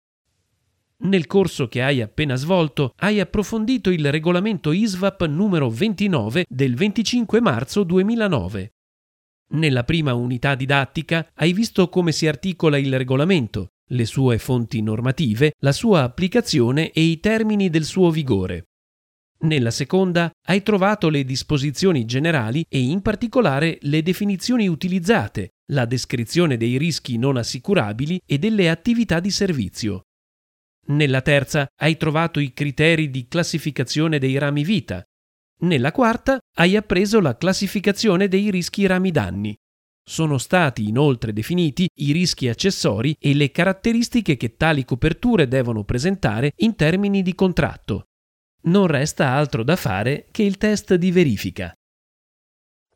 Sprecher italienisch.
Sprechprobe: Sonstiges (Muttersprache):